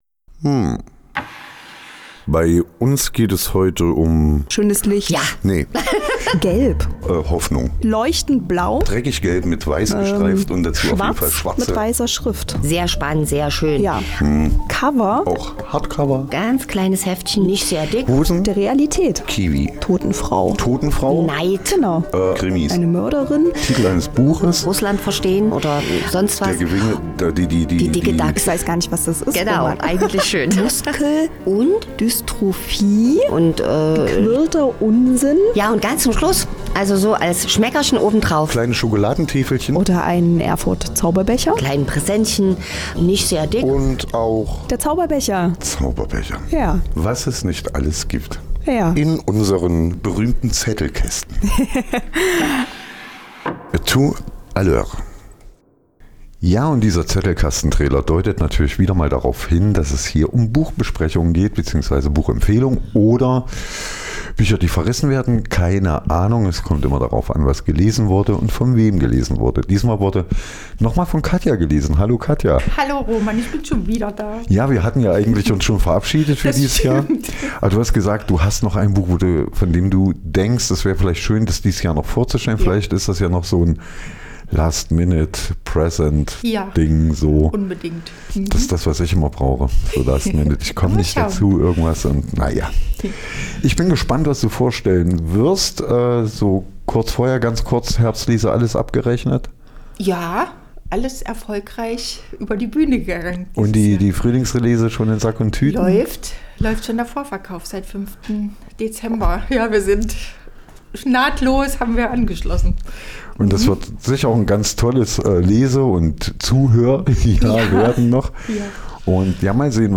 Zettelkasten - die aktuelle Buchbesprechung | Giulia Enders - Organisch